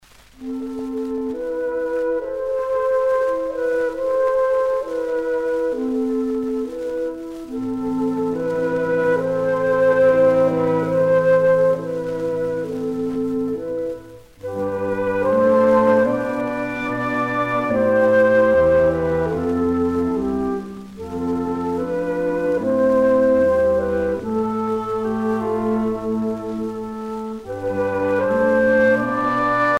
Songs and dances
Pièce musicale éditée